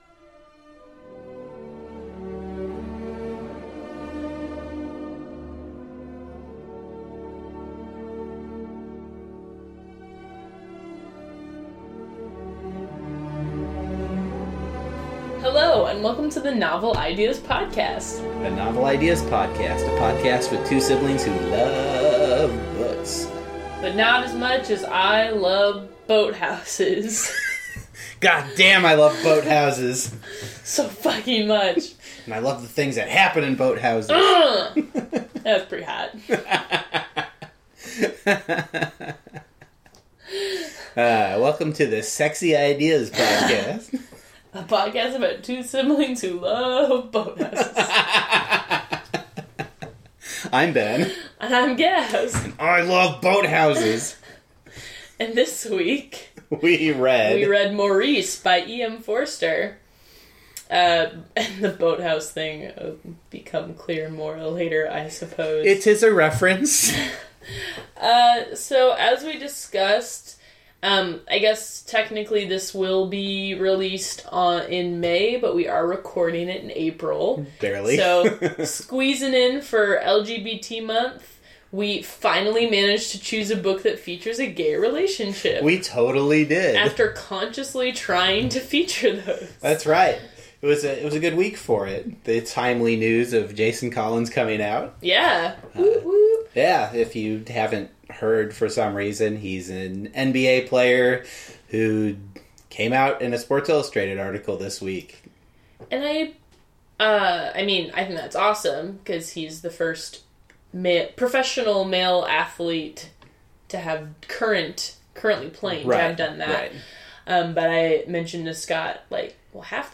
The music bump is from the first movement of Tchaikovsky’s Sixth Symphony, commonly referred to as the “Pathetique” symphony.